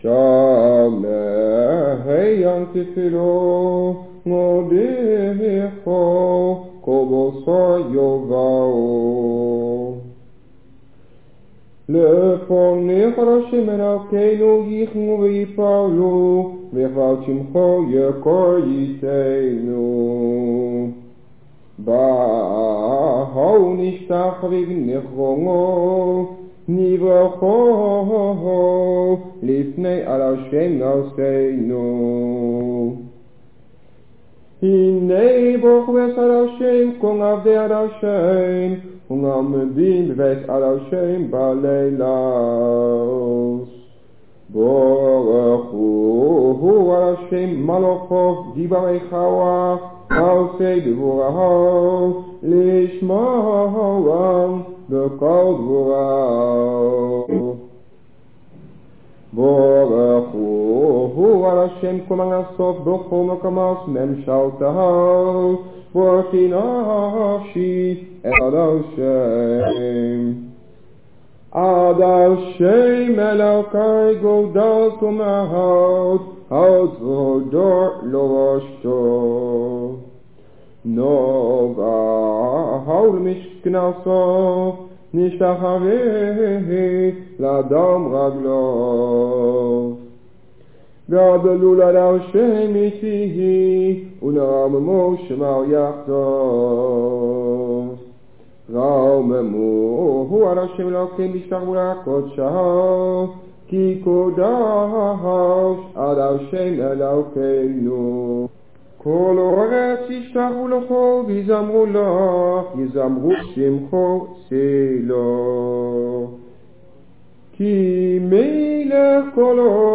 op cassettebandjes.
In dat geval worden alleen de eerste paar woorden en de laatste regel(s), als op bandje, hardop gezegd.